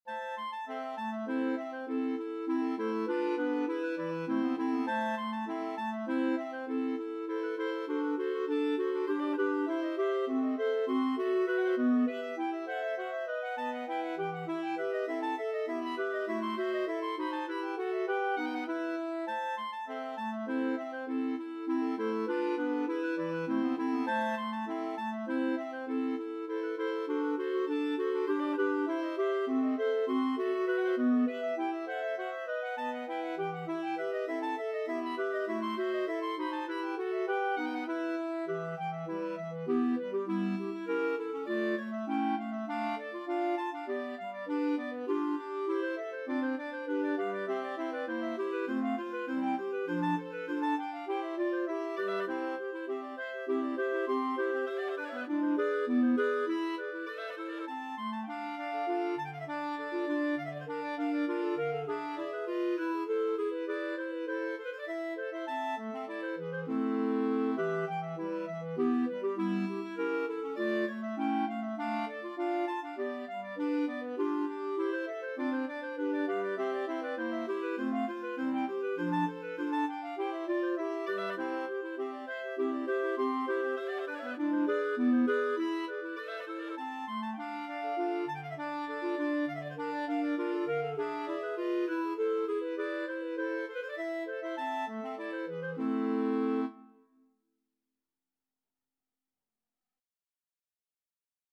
Clarinet Quartet version
2/4 (View more 2/4 Music)
Classical (View more Classical Clarinet Quartet Music)